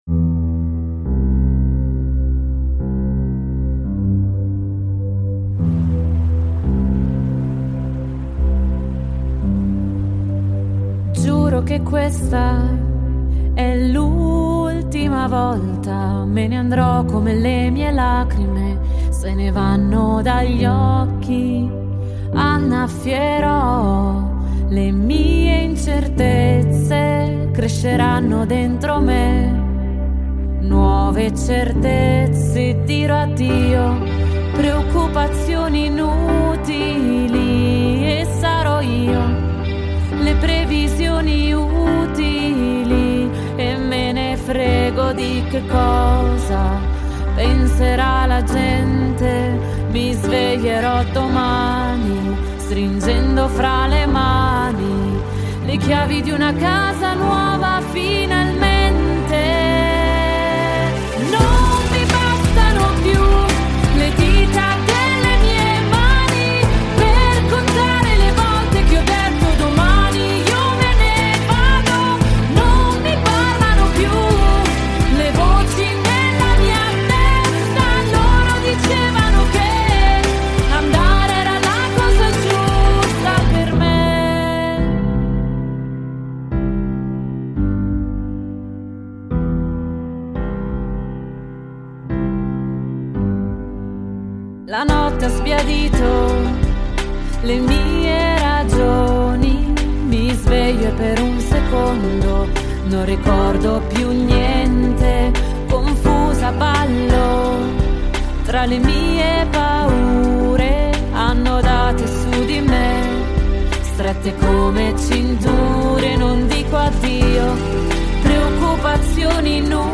اهنگ ایتالیایی خواننده زن